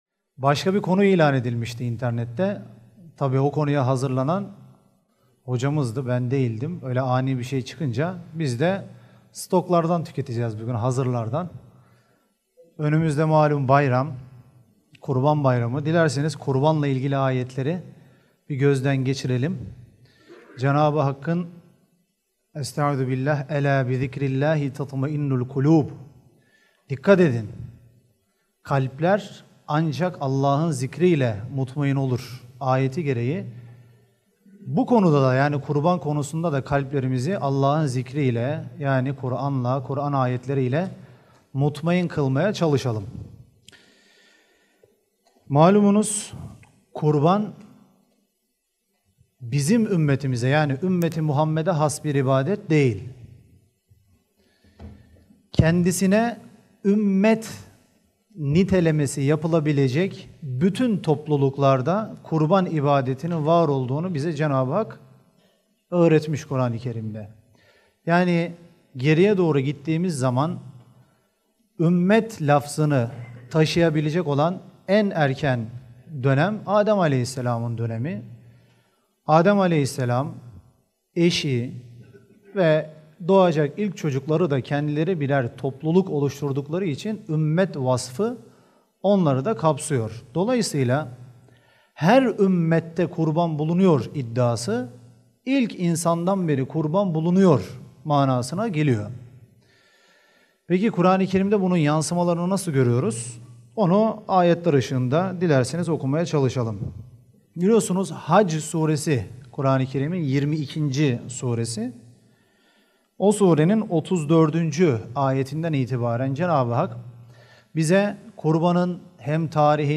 Gösterim: 3.422 görüntülenme Kur'an Sohbetleri Etiketleri: ahlak > bayram > kuran sohbetleri > kurban > kurban bayramı > kurban ibadeti ve bayram ahlakı Başka bir konu ilan edilmişti internette.